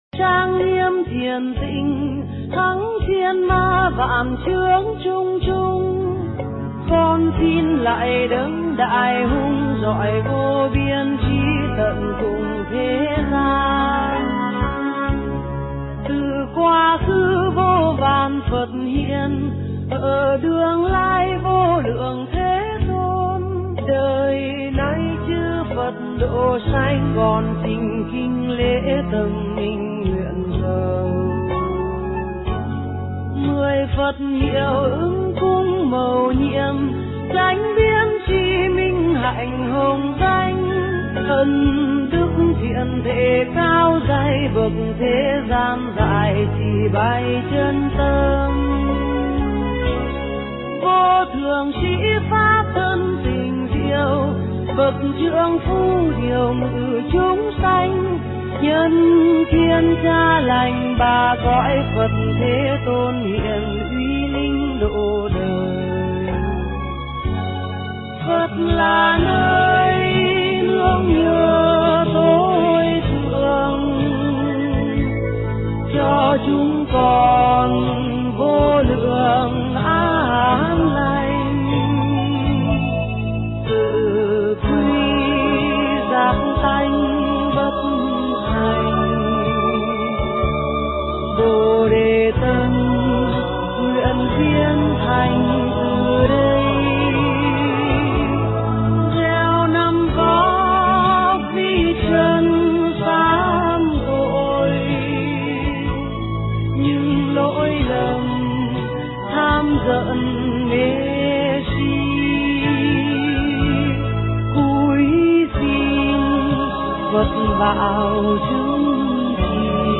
Nghe Mp3 thuyết pháp Hạnh Con Rùa